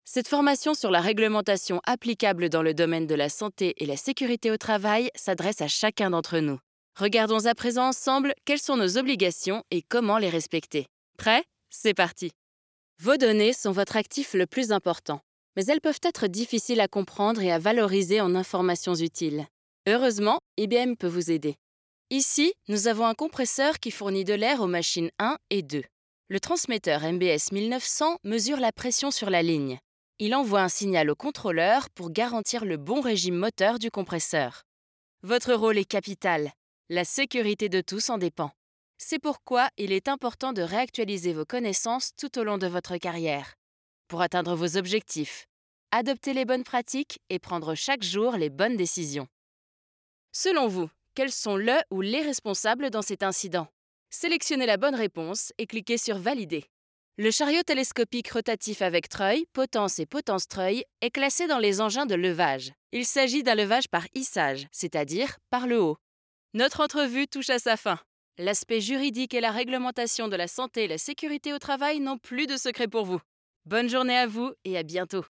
Naturelle, Polyvalente, Amicale
Vidéo explicative